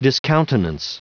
Prononciation du mot : discountenance